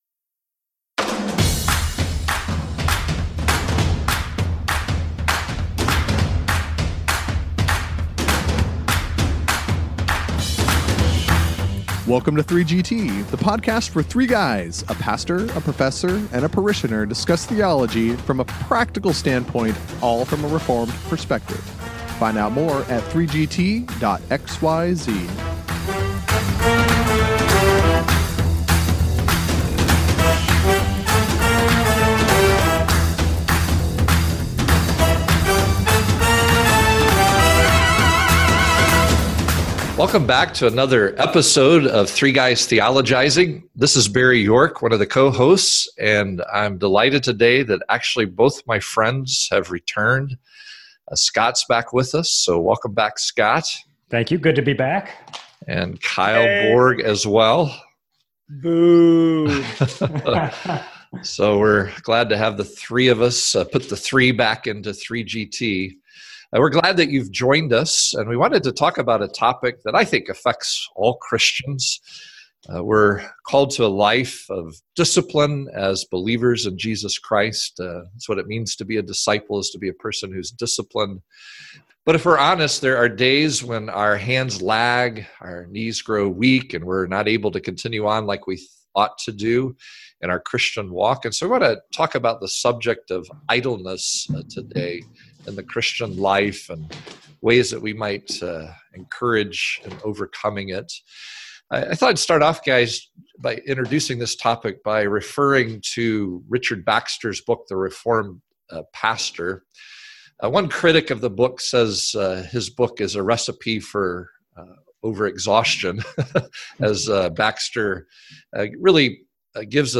Yes, all the guys are back together again and ready to rumble.